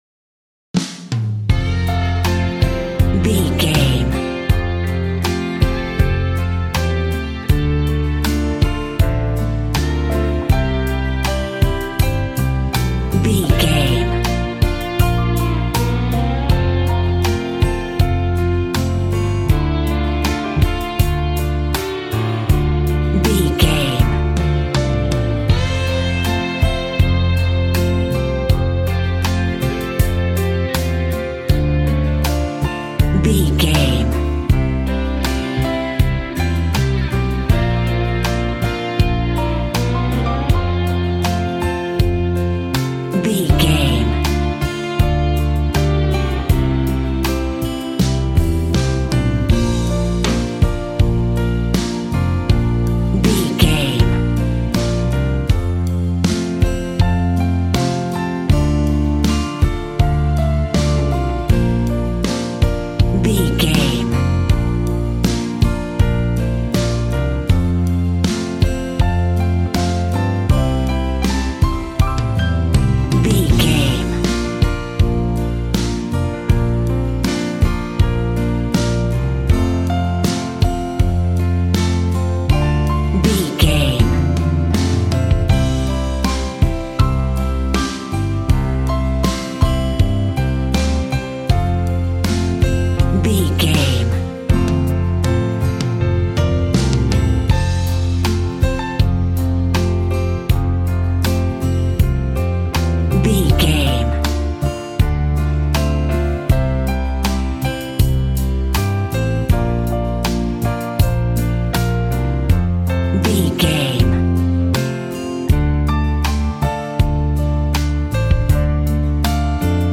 Ionian/Major
cheerful/happy
double bass
drums
piano